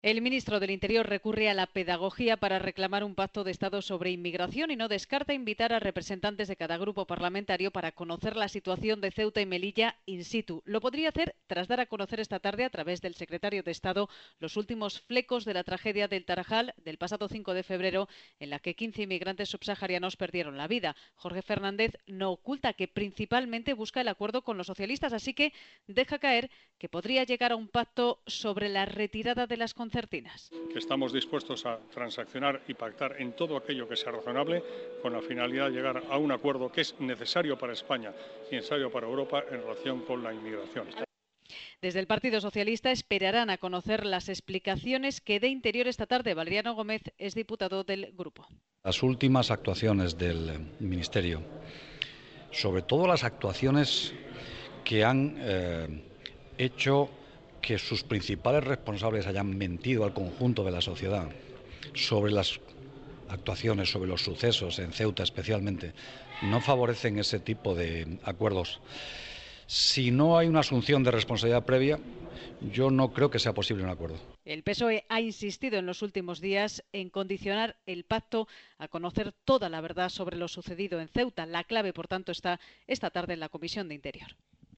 El ministro ha contestado a los periodistas en los pasillos del Congreso preguntado sobre si el ministerio estaría dispuesto a retirar la cuchillas de las vallas de Ceuta y Melilla para lograr el acuerdo.